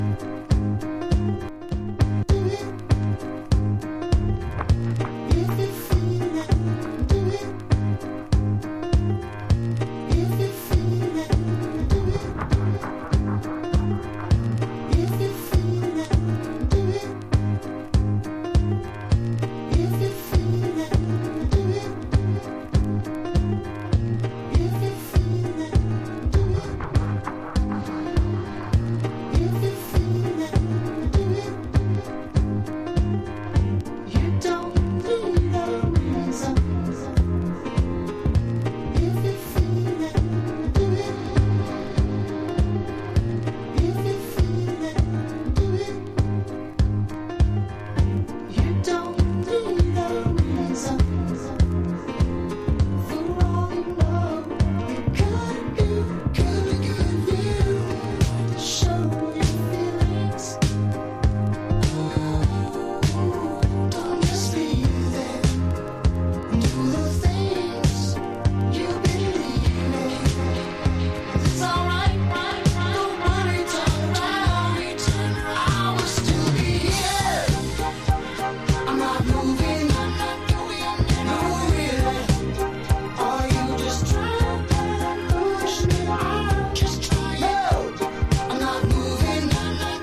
NU-DISCO / RE-EDIT